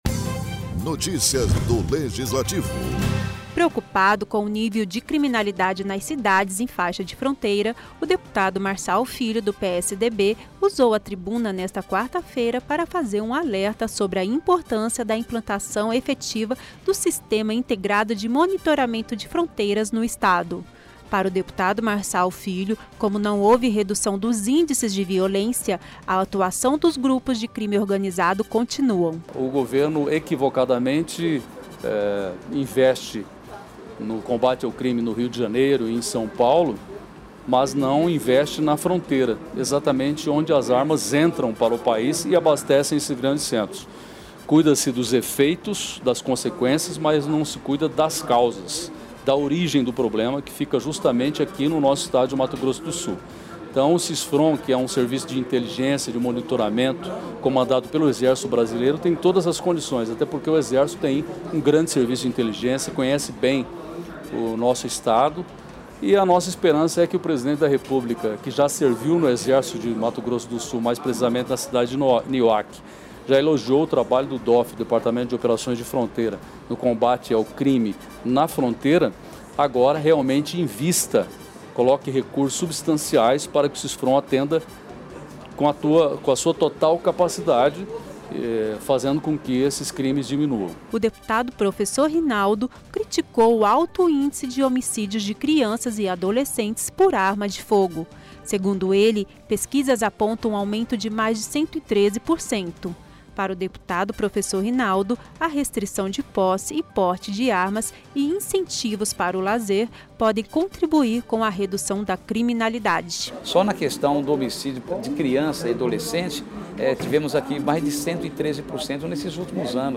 A violência e o armamento no país, foram assuntos debatidos em plenário na manhã desta quarta-feira (20) pelos deputados estaduais Marçal filho e Professor Rinaldo .